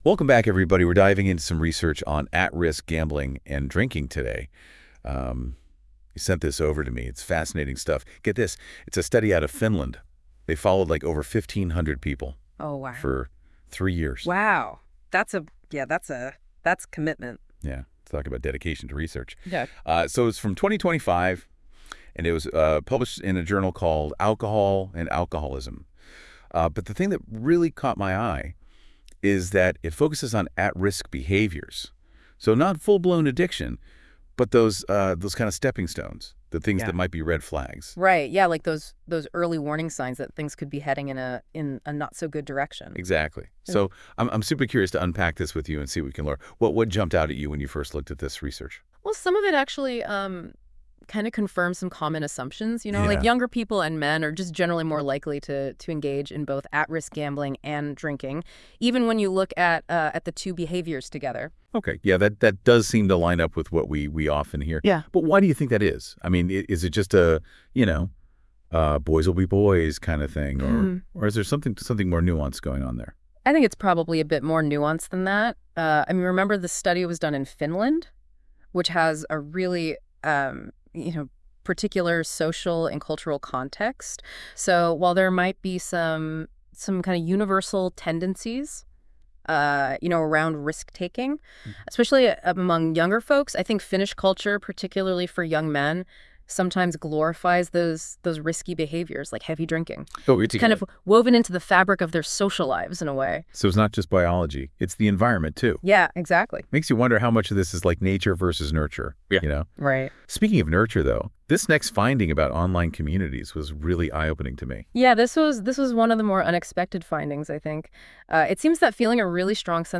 And listen to a podcast (created with NotebookLM:llä):